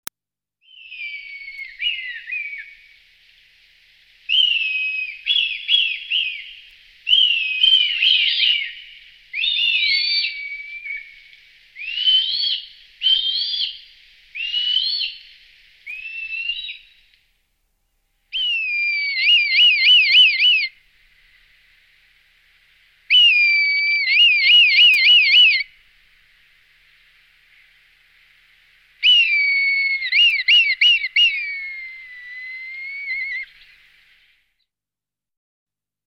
На этой странице собраны разнообразные звуки коршуна – от резких криков до переливчатых трелей.
Крик красного коршуна в звуке